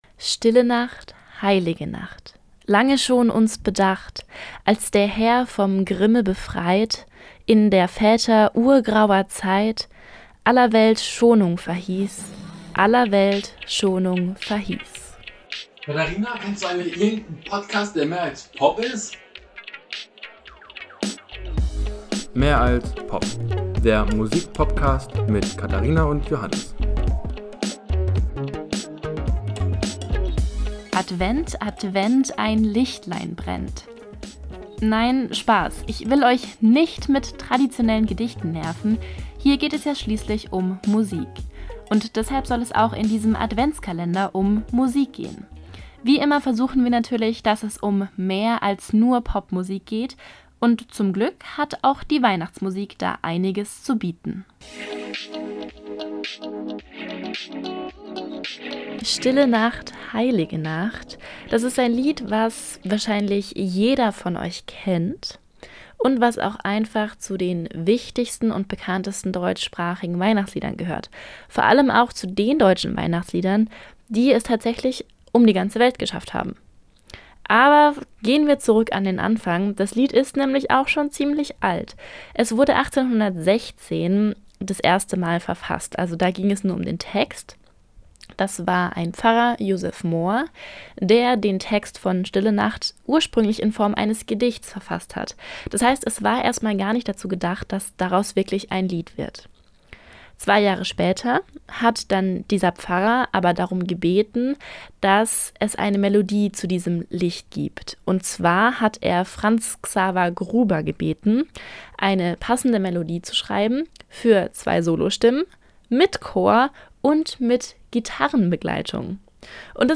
Die Musik für Intro und Outro ist von WatR.